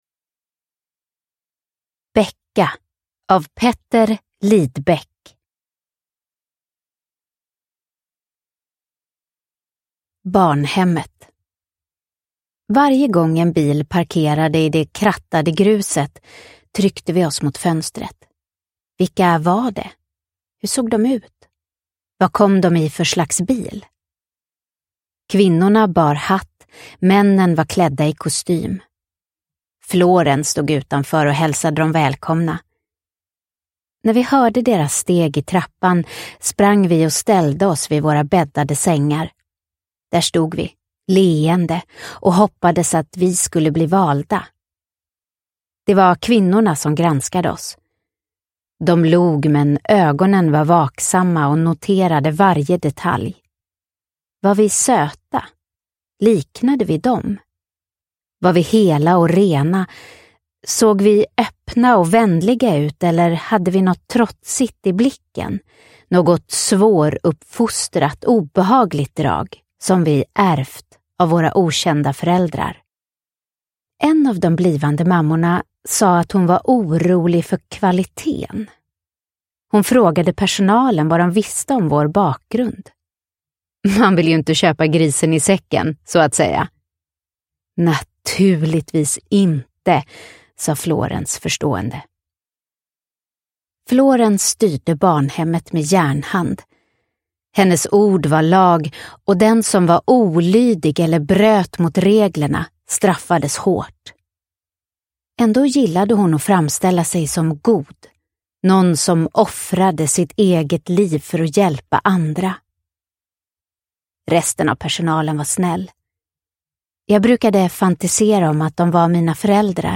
Becca – Ljudbok – Laddas ner